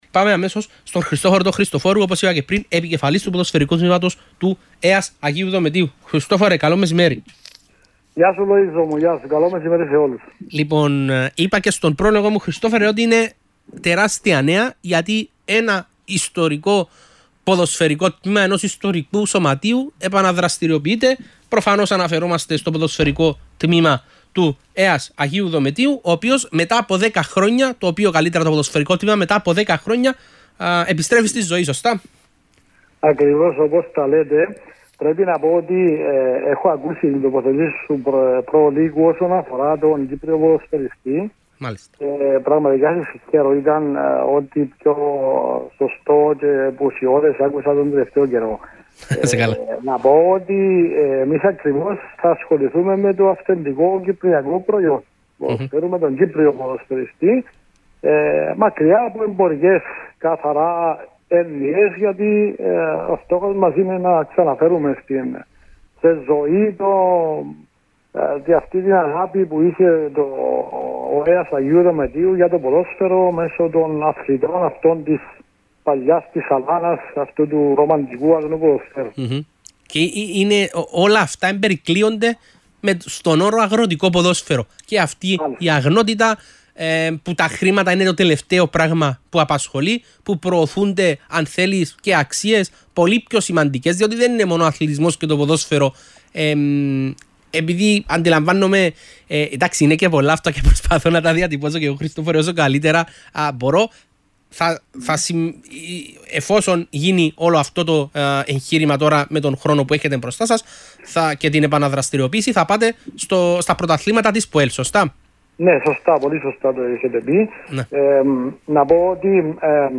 μίλησε στην εκπομπή του Άστρα 92.8